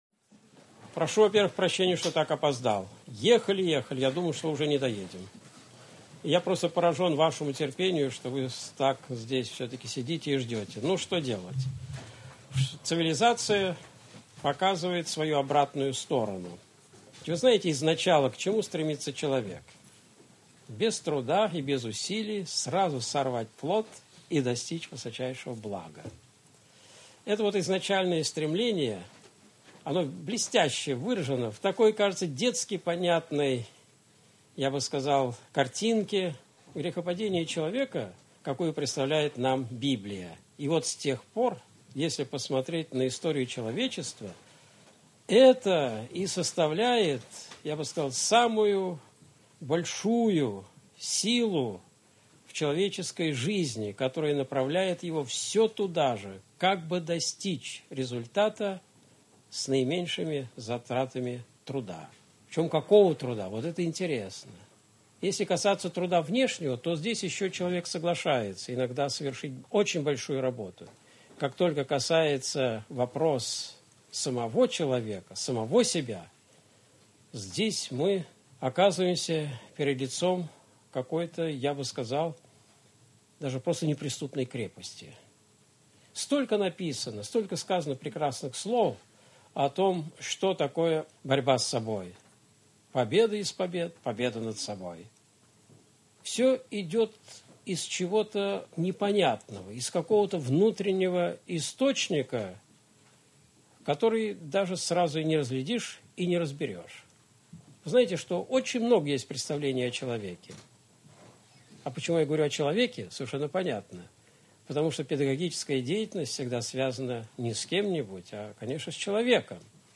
Когда жизнь бессмысленна и бесцельна — Лекция профессора Осипова
Вопросы аудитории: